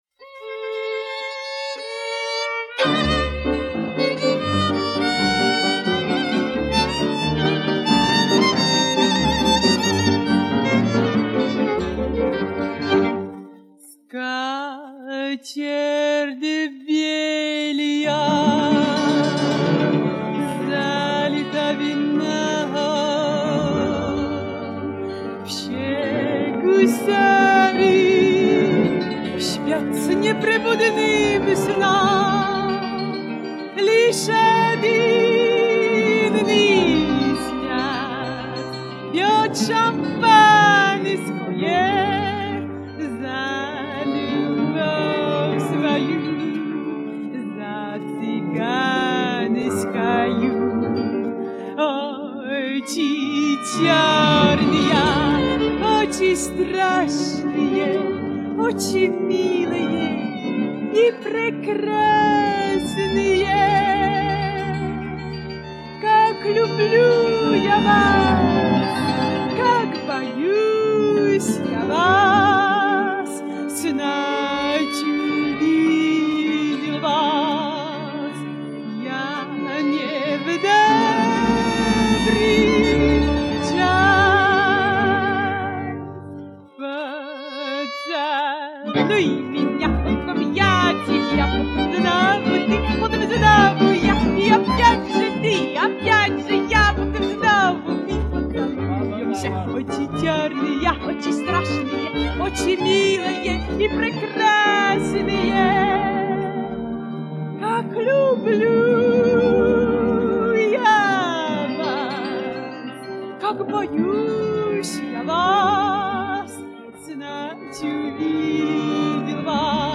цыганского оркестра из Нидерландов
романc